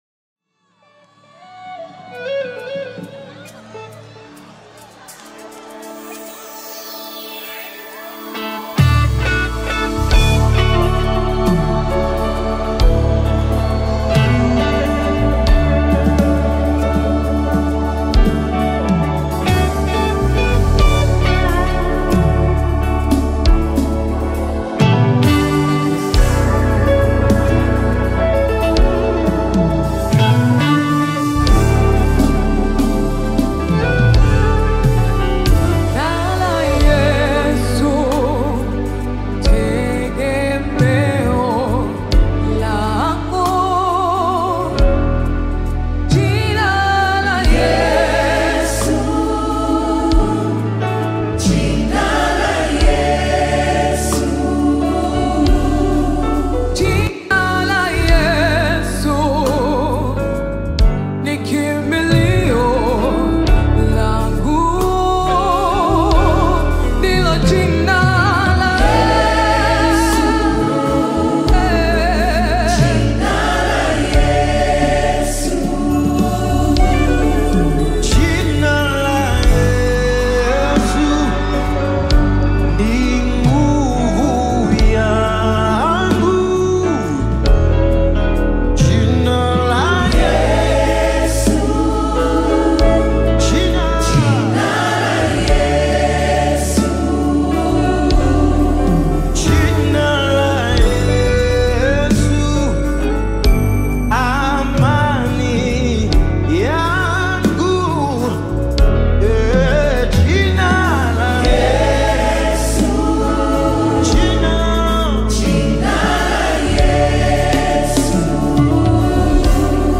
Tanzanian gospel